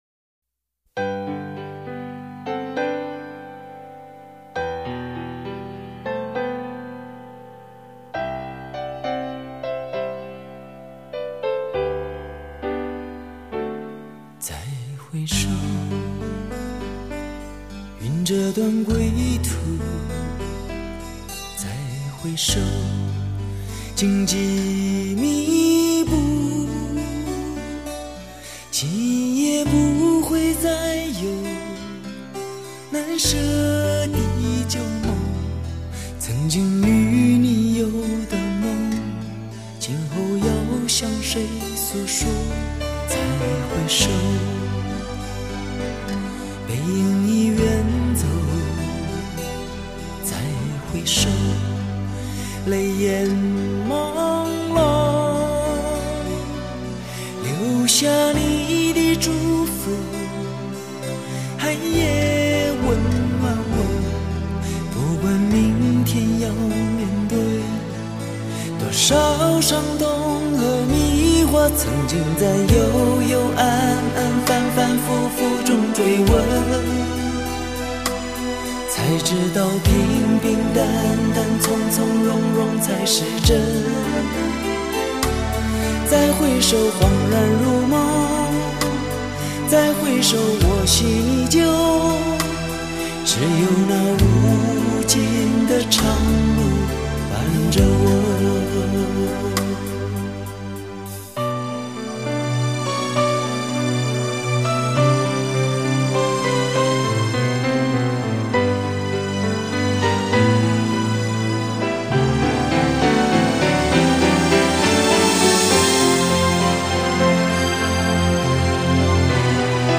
典藏最具时代影响力的经典流行佳作